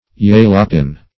Search Result for " jalapin" : The Collaborative International Dictionary of English v.0.48: Jalapin \Jal"a*pin\, n. (Chem.)